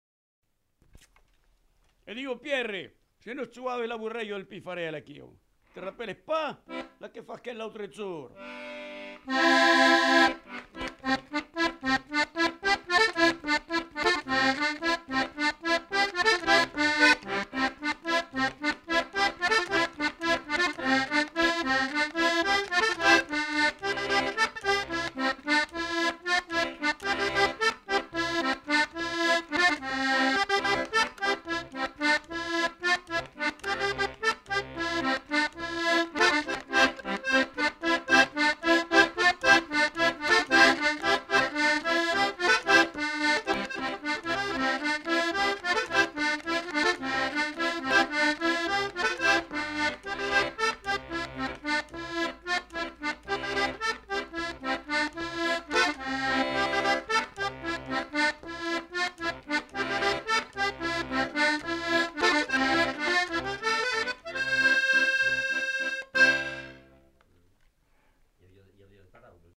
Répertoire d'airs à danser joué à l'accordéon diatonique ou chanté
enquêtes sonores